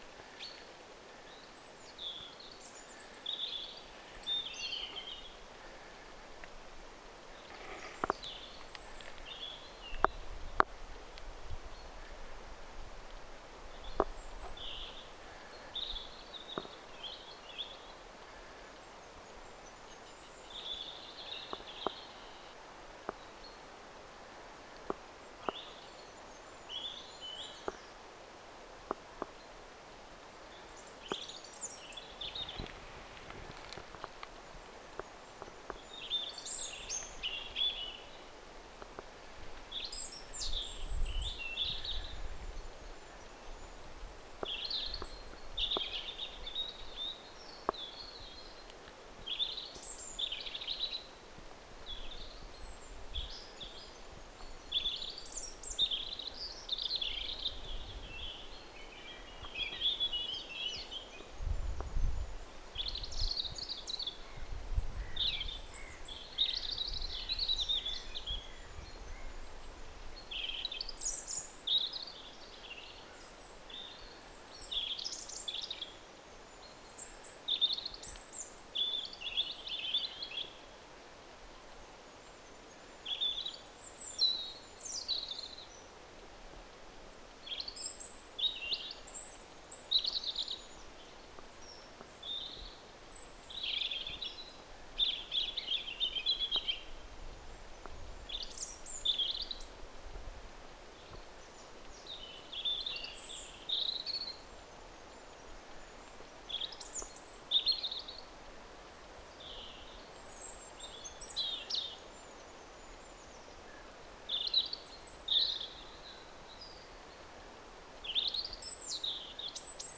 Tässä on neljä tai viisi laulavaa punarintaa.
4. ja 5. punarinta
4s_ja_5s_punarinta_laulaa.mp3